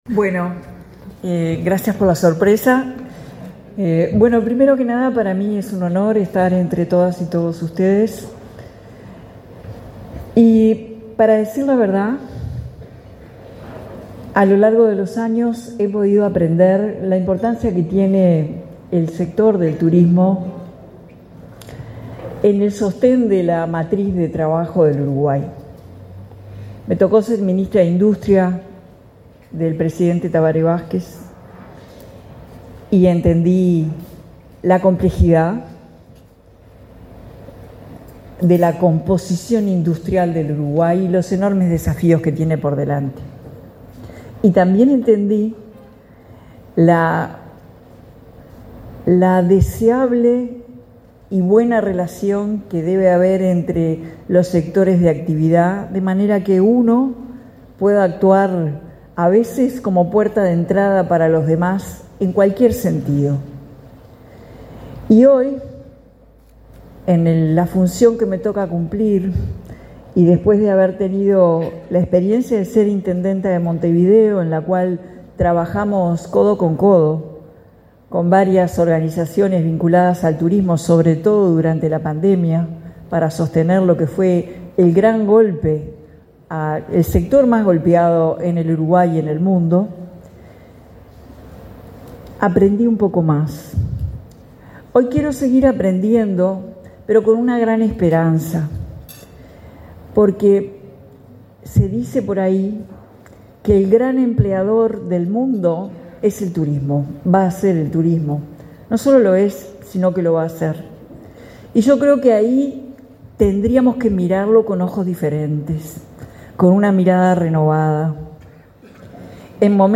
Palabras de la presidenta en ejercicio, Carolina Cosse
La presidenta de la República en ejercicio, Carolina Cosse, participó en la conmemoración del Día Mundial del Turismo, organizado por la Cámara